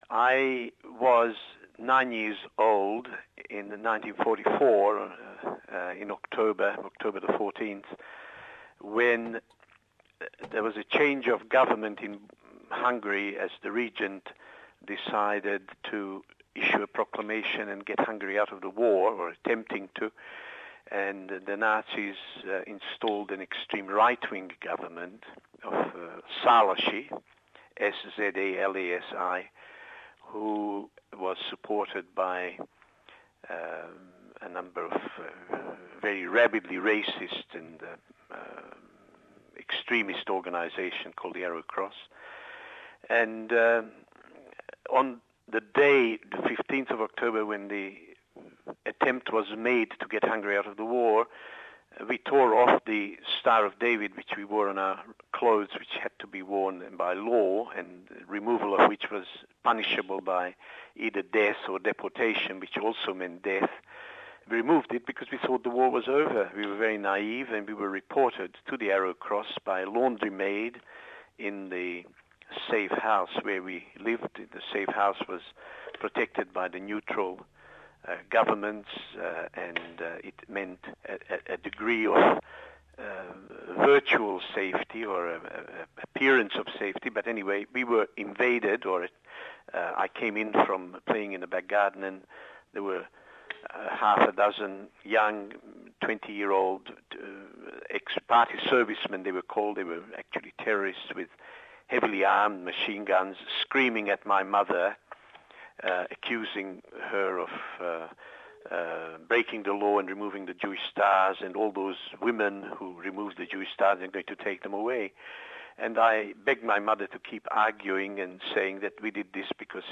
Testimony nr 2 : Oral testimony